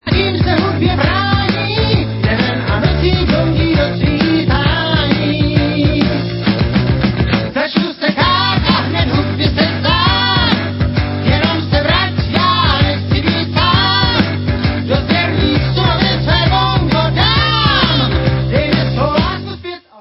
Rockové základy byly doplněny elektronickými klávesami
Bonusy rozšiřují obsazení o další muzikanty